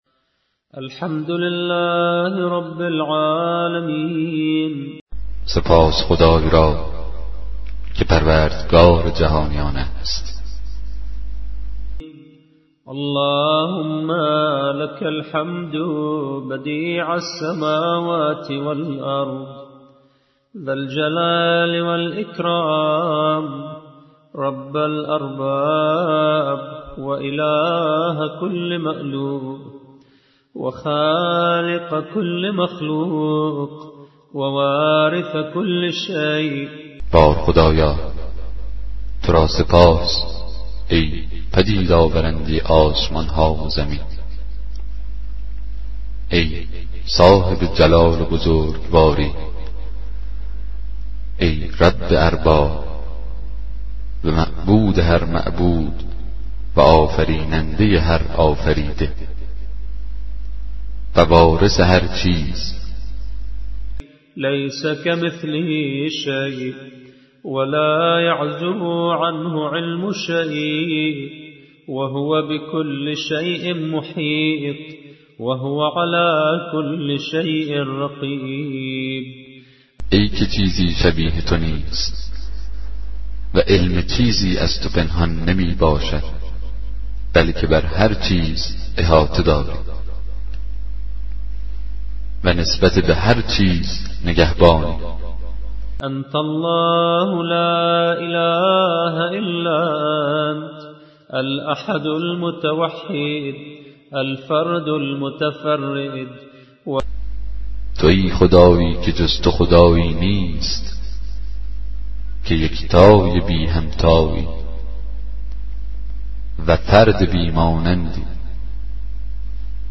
کتاب صوتی دعای 47 صحیفه سجادیه